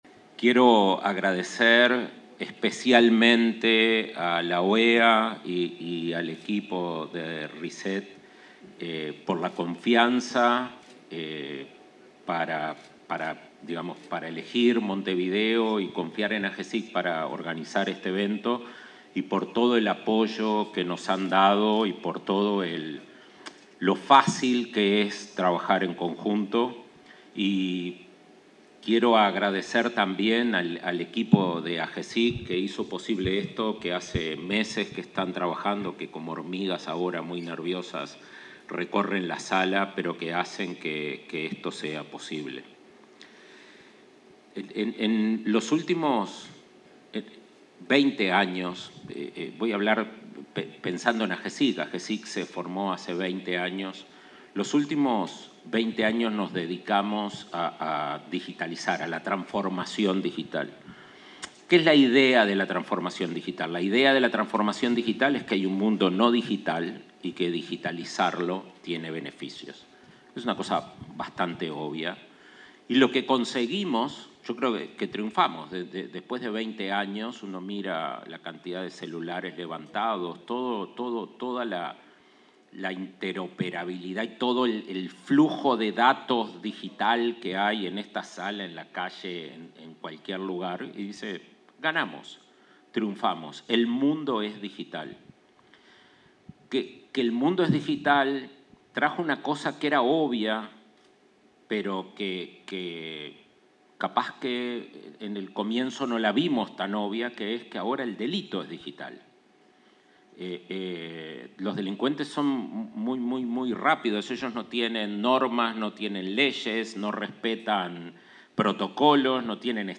Palabras del director de Agesic, Daniel Mordecki
El director de la Agencia de Gobierno Electrónico y Sociedad de la Información y del Conocimiento (Agesic), Daniel Mordecki, se expresó en el simposio
oratoria.mp3